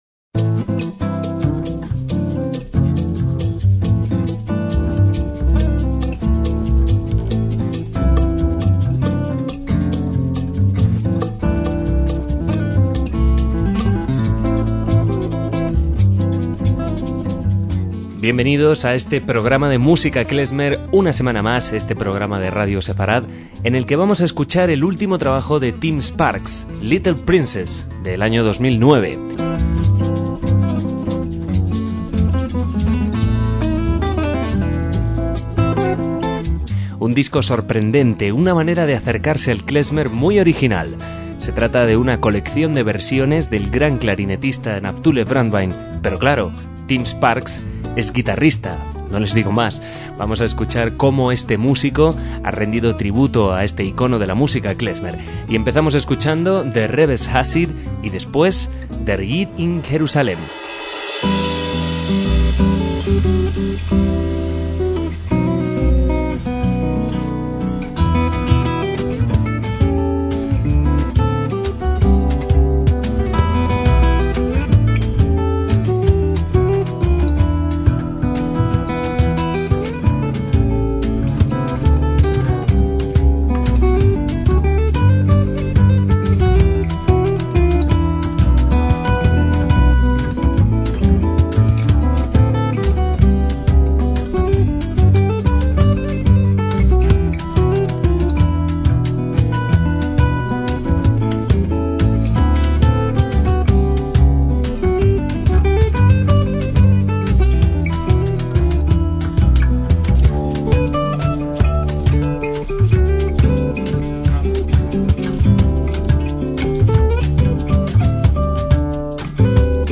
MÚSICA KLEZMER
guitarrista